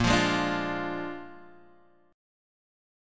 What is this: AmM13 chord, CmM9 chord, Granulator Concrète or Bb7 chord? Bb7 chord